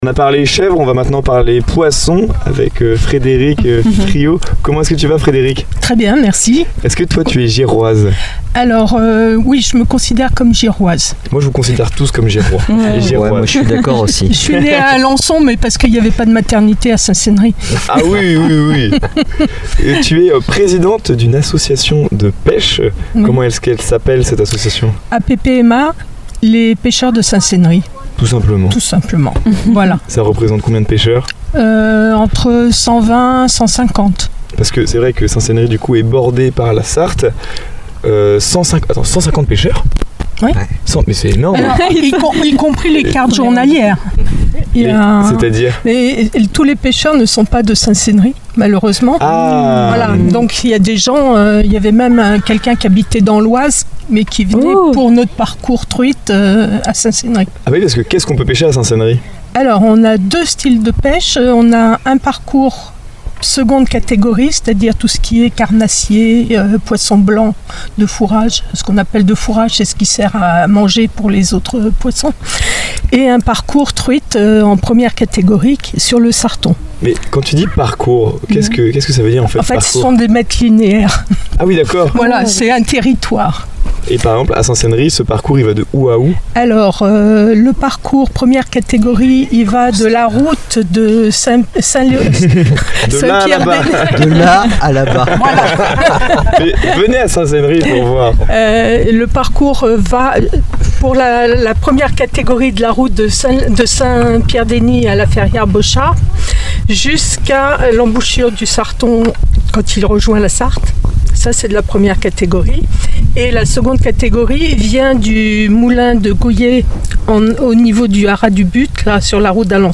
Une interview nature et engagée qui met en lumière la passion des bénévoles et la beauté d’un territoire où patrimoine et environnement se conjuguent au fil de l’eau.